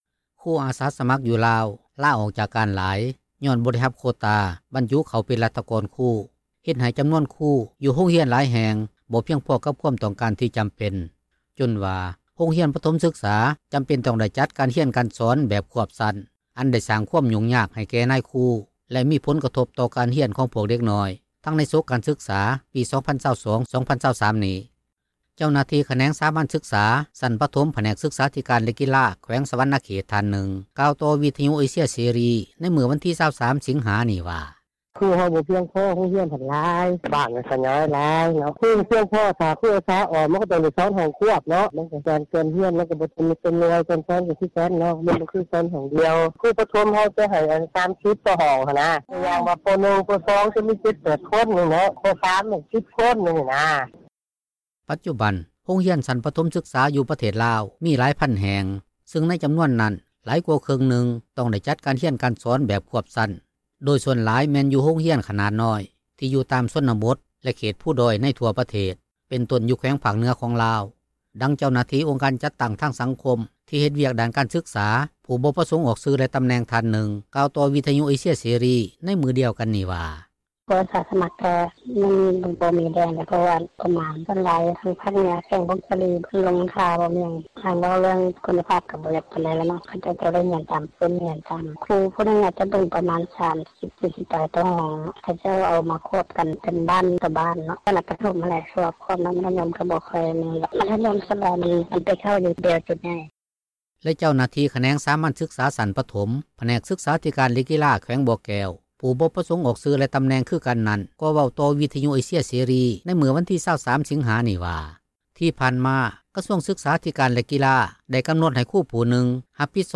ດັ່ງທີ່ນາງກ່າວວ່າ: